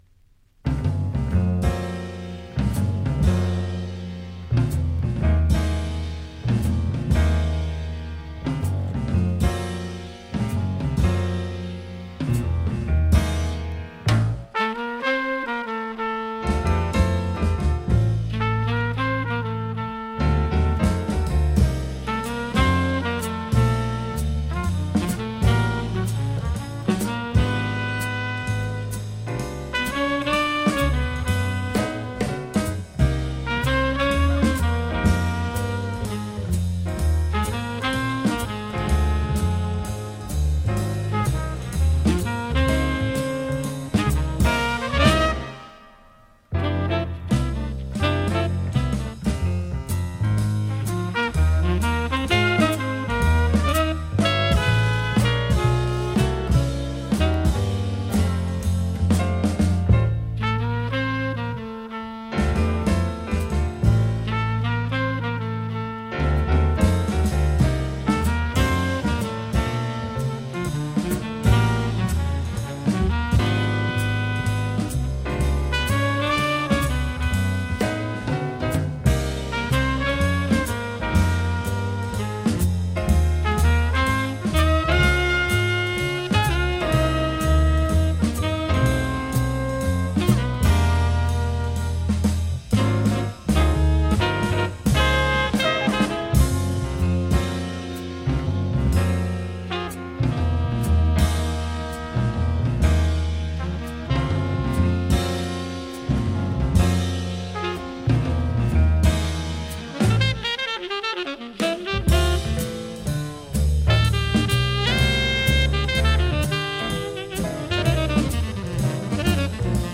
洗練された美メロのモーダル・ジャズ